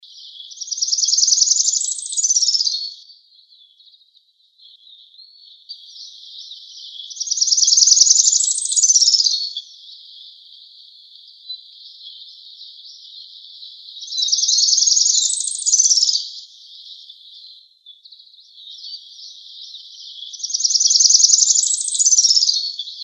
Tropical Parula (Setophaga pitiayumi)
Life Stage: Adult
Location or protected area: Reserva Ecológica Costanera Sur (RECS)
Condition: Wild
Certainty: Recorded vocal